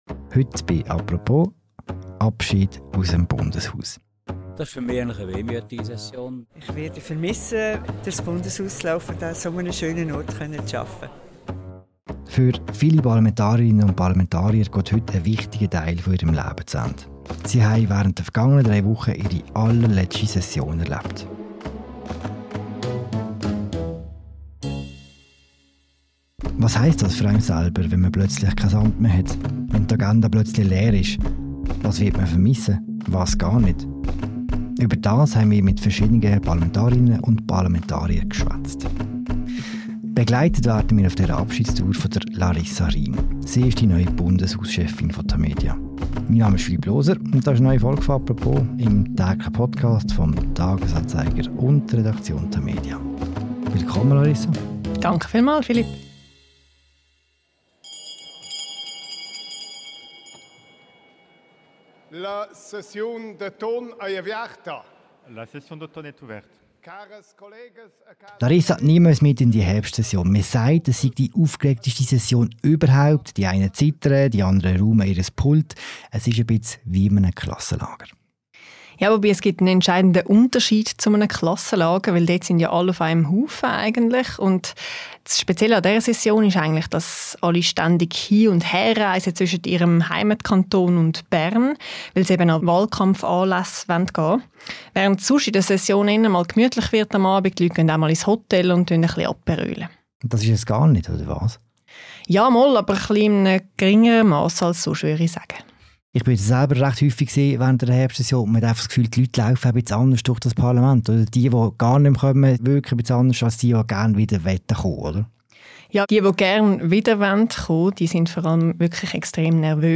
«Apropos» fragt bei sieben Abtretenden nach.
Ein letztes Mal stehen Prisca Birrer-Heimo, Kurt Fluri, Ida Glanzmann, Peter Keller, Alex Kuprecht, Martin Landolt und Christa Markwalder in der Wandelhalle Rede und Antwort.